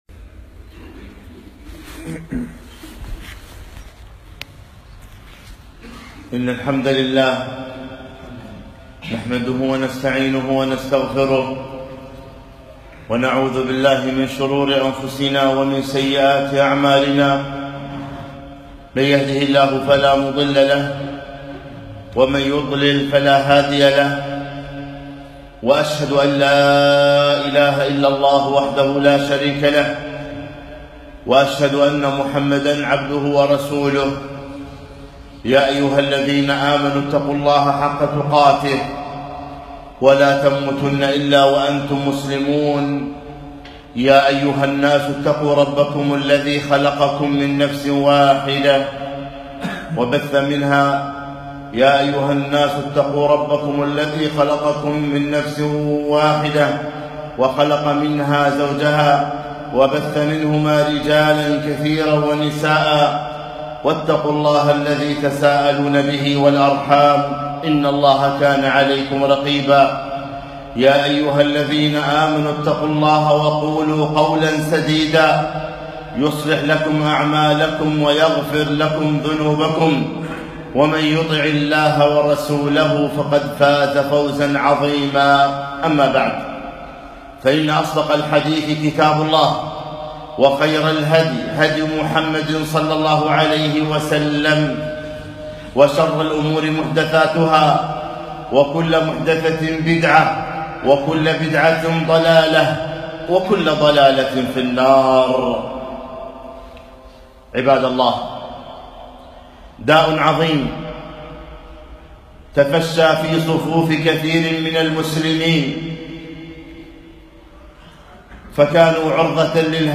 خطبة - إلى متى الغفلة ؟!